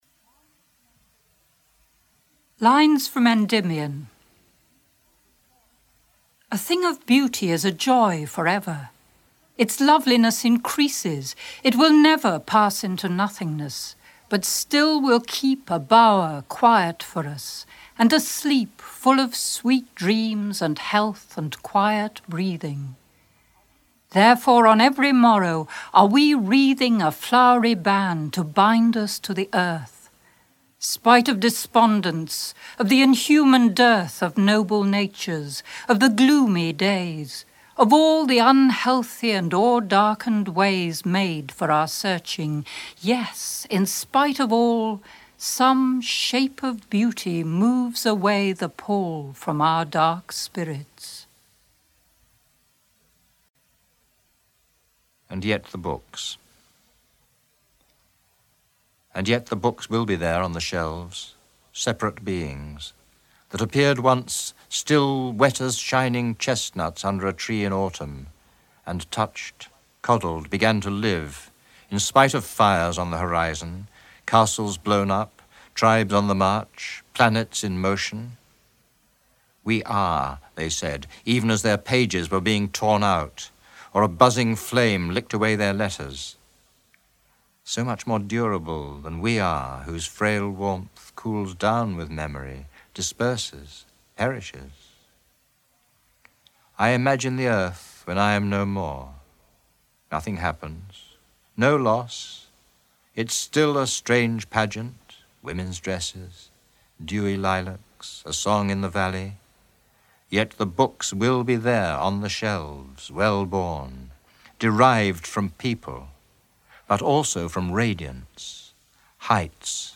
Everyone Sang by Siegfried Sassoon read by Adrian Mitchell
The Uncertainty of the Poet by Wendy Cope read by Wendy Cope
One Art by Elizabeth Bishop read by Ruth Fainlight